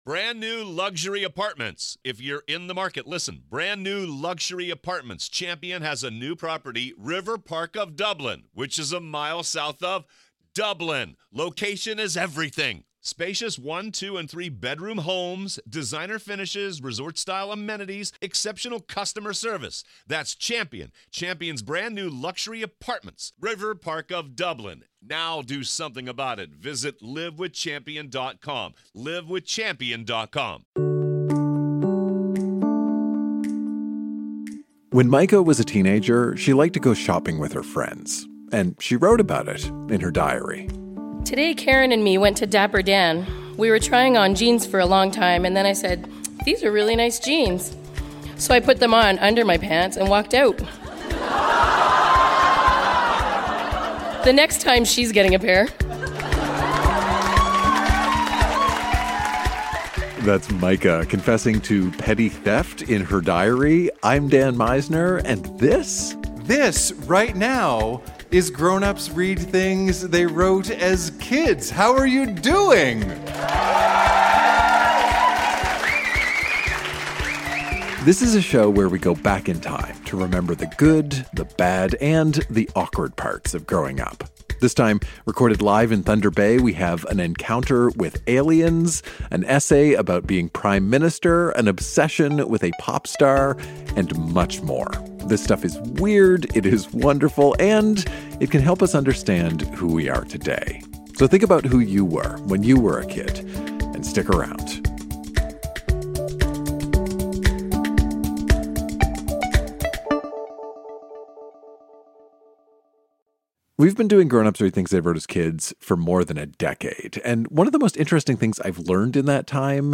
Recorded live at The Finlandia Club in Thunder Bay.